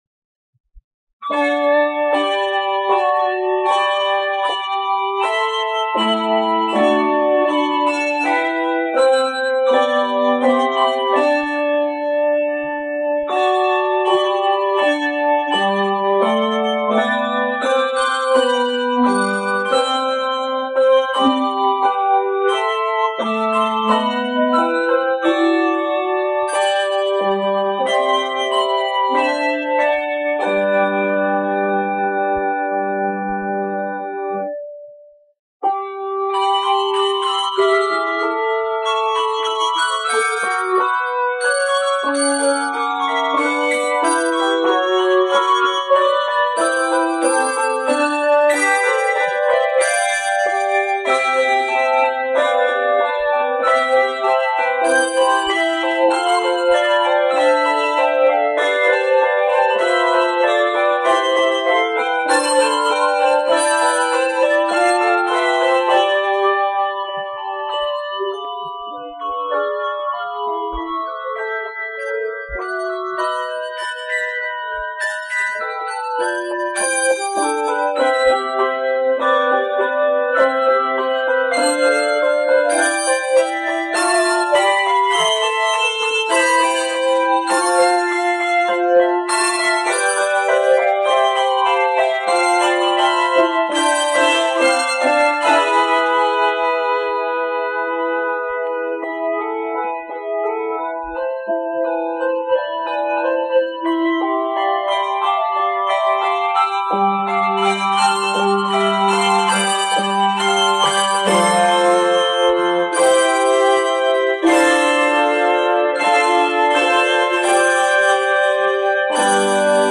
The Prelude is set in C Major.